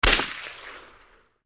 GUNSHOT.mp3